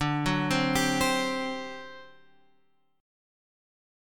D11 chord {10 9 10 x 8 8} chord